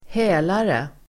Ladda ner uttalet
hälare substantiv, fence , receiver of stolen goods Uttal: [²h'ä:lare] Böjningar: hälaren, hälare, hälarna Definition: person som köper och gömmer tjuvgods (a person who purchases and conceals stolen goods)